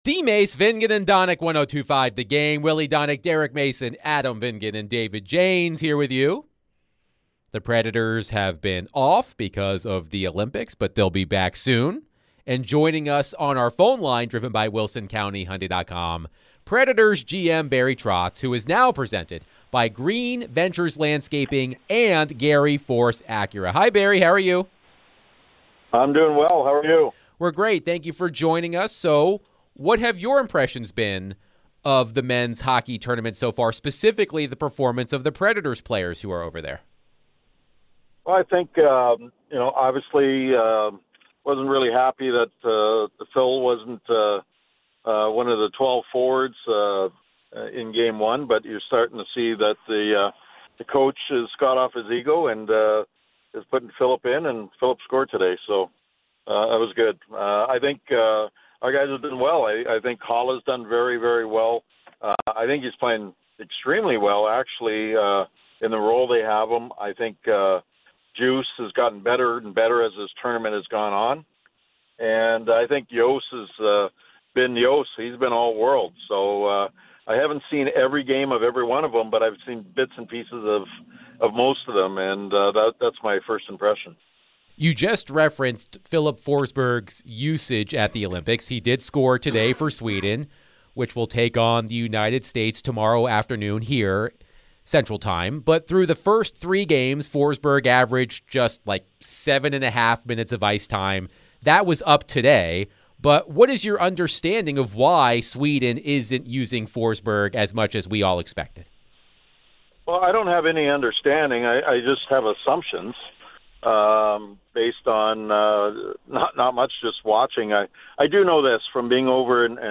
The Nashville Predators General Manager, Barry Trotz, joined DVD for his weekly chat.